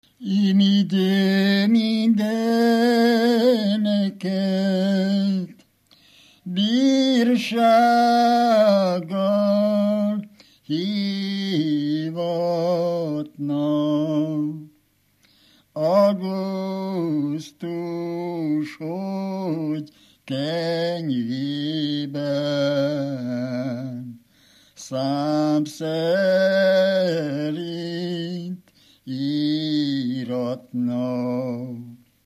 Moldva és Bukovina - Bukovina - Istensegíts
Stílus: 5. Rákóczi dallamkör és fríg környezete
Szótagszám: 7.6.7.6
Kadencia: 5 (b3) 2 1